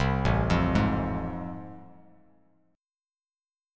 AmM7#5 Chord